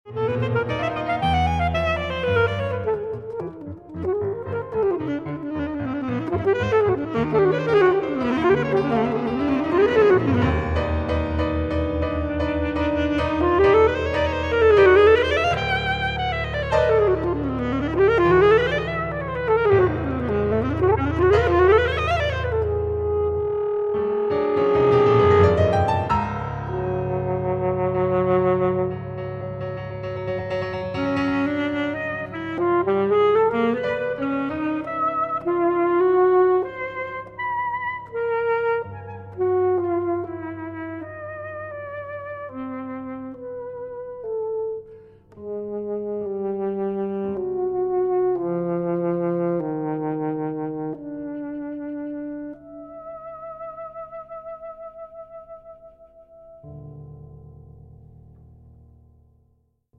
Instrumentation: alto saxophone, piano